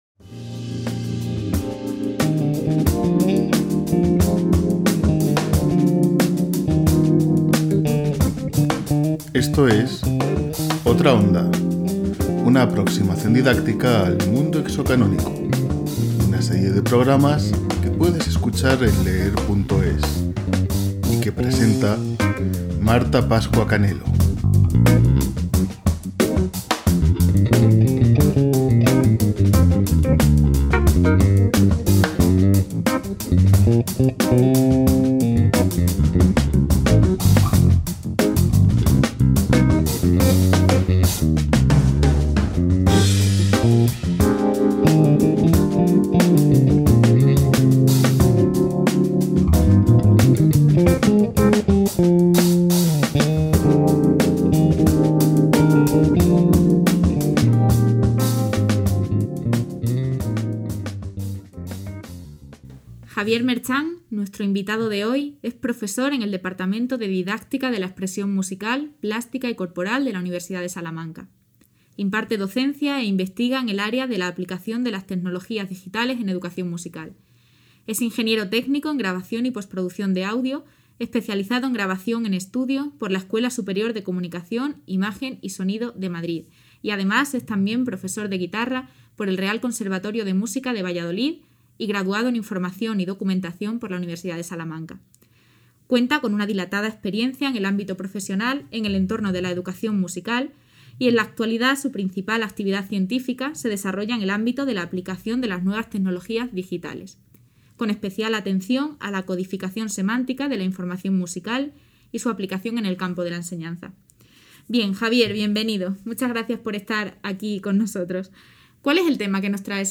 Esta serie de pódcasts ofrece a los docentes y estudiantes interesados siete capítulos en los que varios expertos de la Universidad de Salamanca son entrevistados para hablar de las ventajas y posibilidades educativas de introducir en el aula objetos culturales ajenos al canon cultural dominante. Se exponen cuestiones relevantes, útiles e interesantes para la sociedad actual que han quedado tradicionalmente fuera del canon escolar: la ciencia ficción, los videojuegos, el rock progresivo o la realidad de las personas trans son algunas de las materias abordadas a lo largo de estos programas.